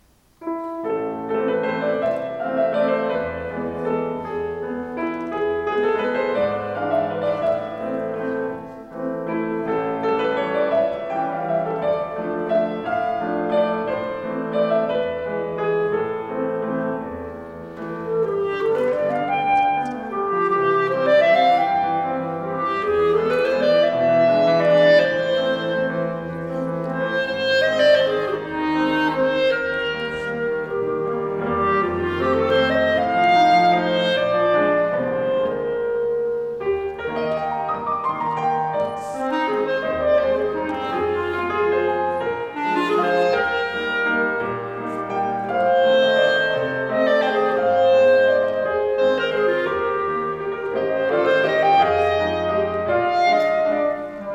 • Partition pour clarinette